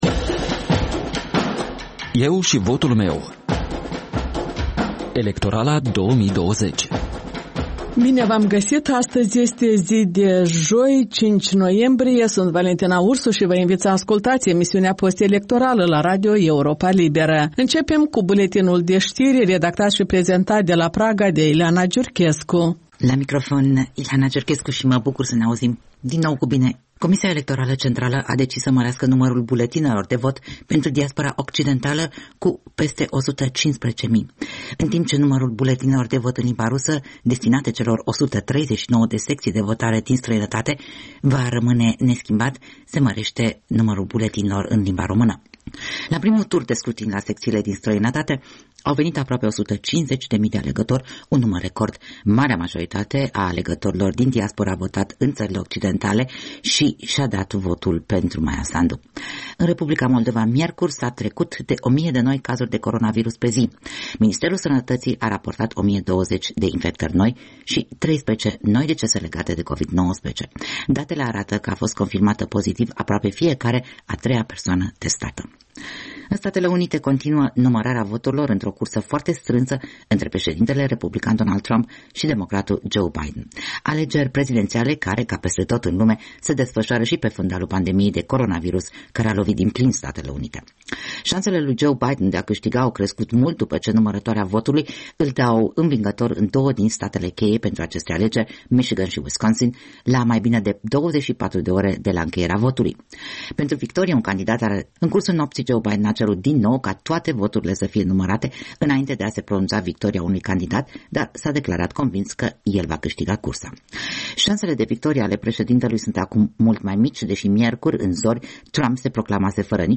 De luni până vineri, de la ora 13.00, radio Europa Liberă prezintă interviuri cu candidații în alegerile prezidențiale din 15 noiembrie, discuții cu analiști și formatori de opinie, vocea străzii și cea a diasporei.